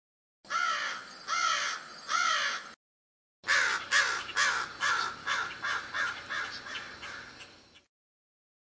Download Crow sound effect for free.
Crow